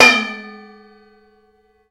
PRC BOWL H0A.wav